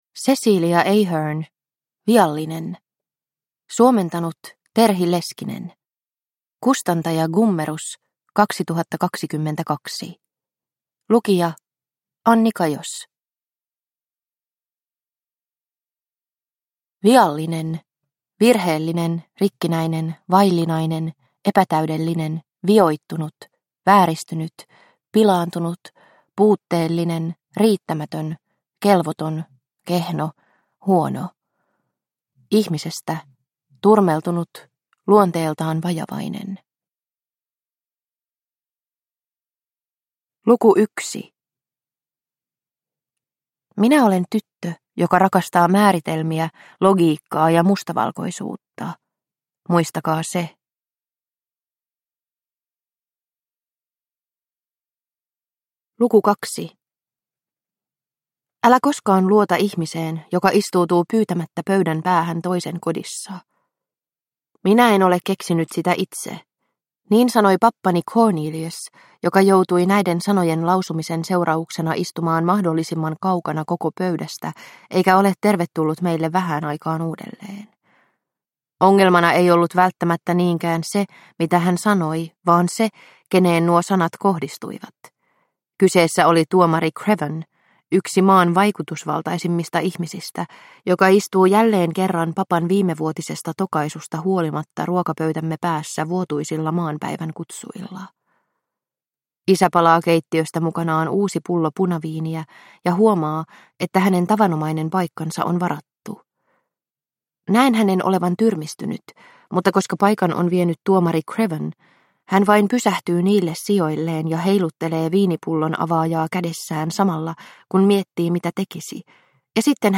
Viallinen – Ljudbok – Laddas ner